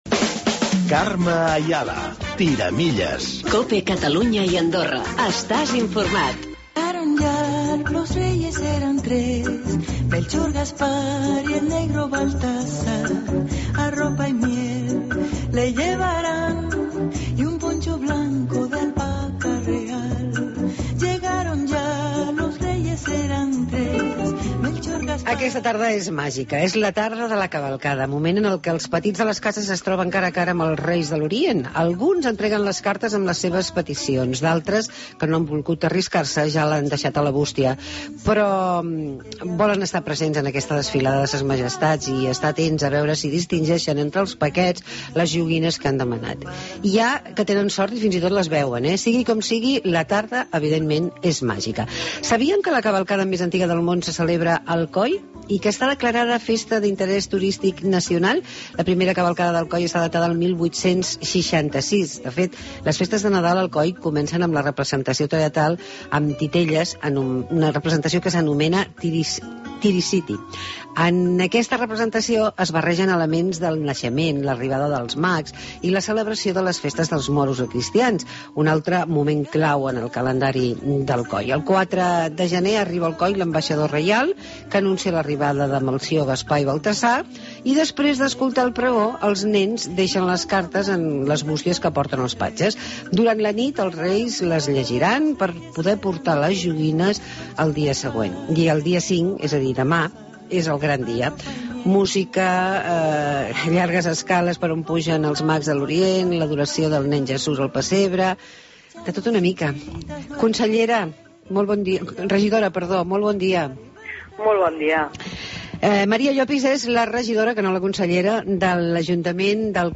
Entrevista amb la regidora de Turisme d'Alcoi sobre la cavalcada de Reis més antiga.